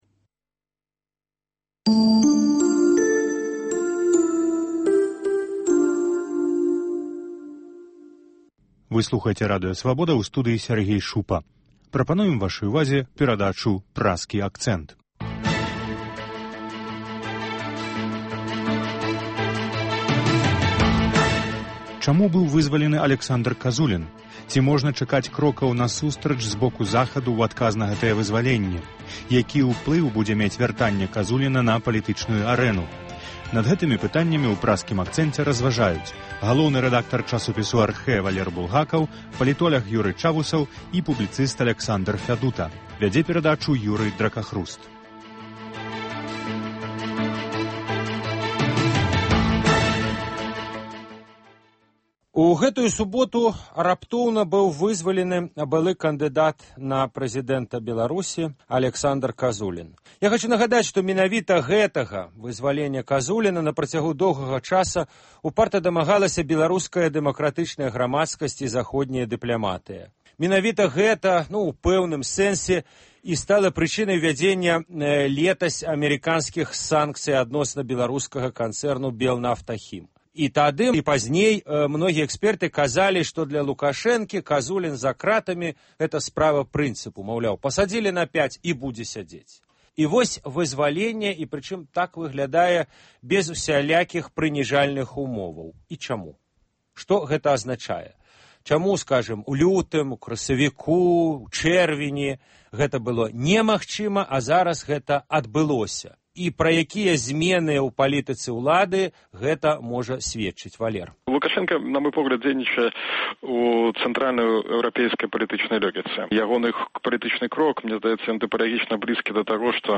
Круглы стол аналітыкаў, абмеркаваньне галоўных падзеяў тыдня